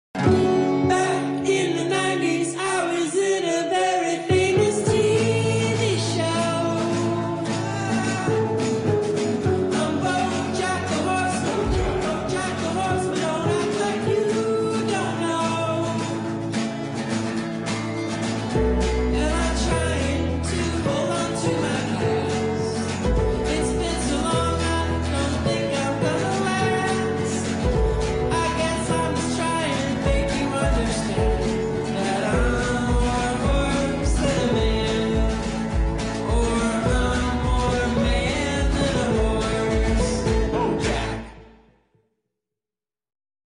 • Качество: 128, Stereo
мужской вокал
alternative
indie rock